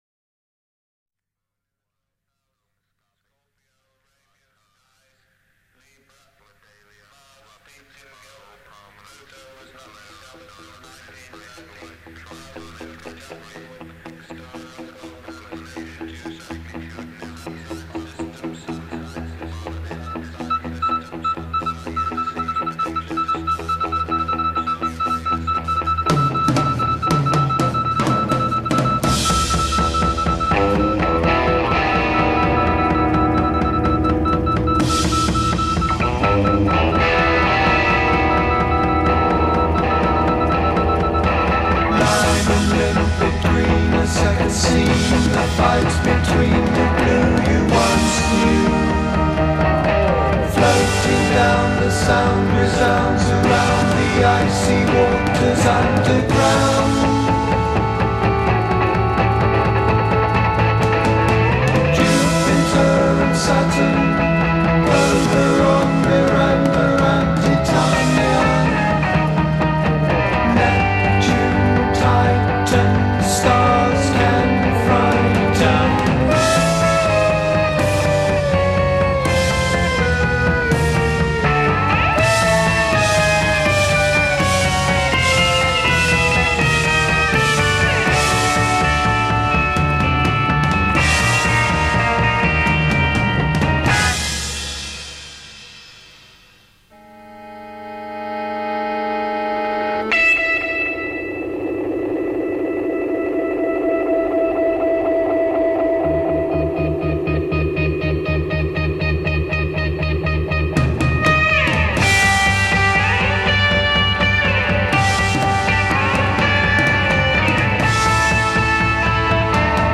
Lead Guitar & Vocals
Bass Guitar & Vocals
Organ & Piano
Drums